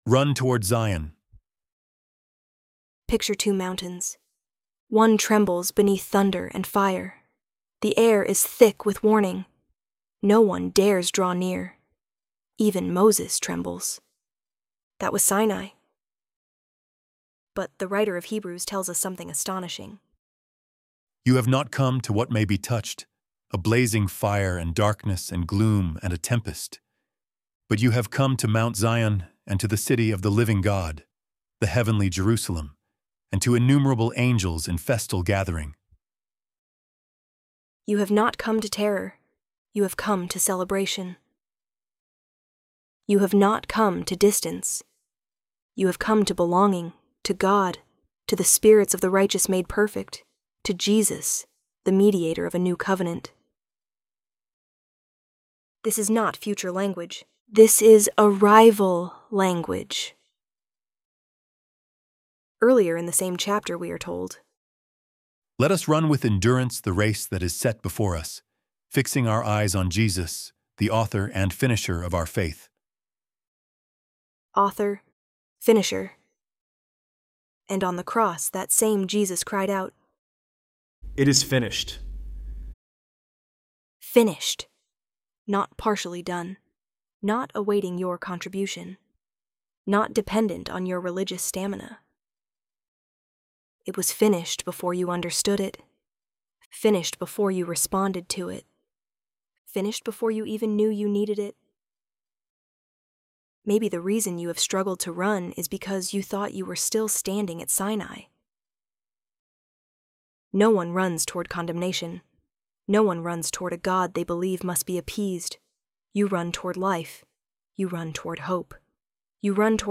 ElevenLabs_RUN_TOWARD_ZION-2.mp3